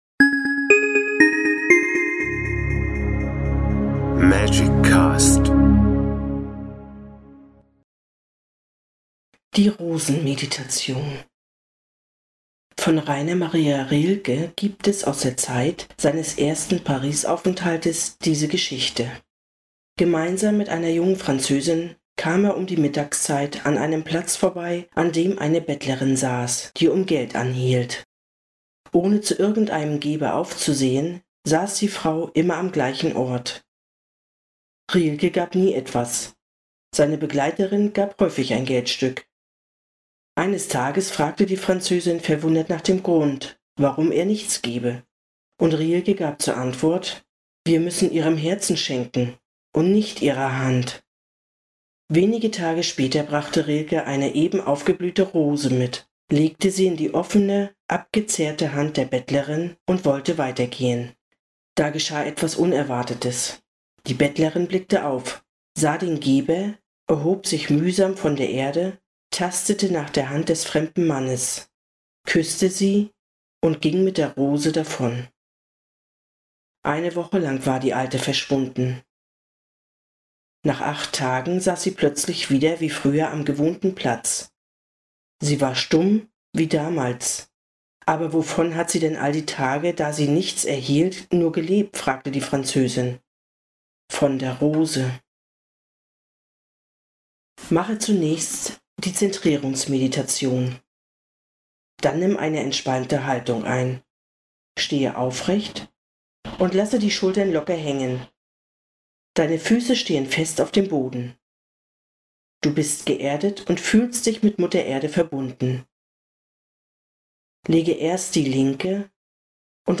Die Rosenmeditation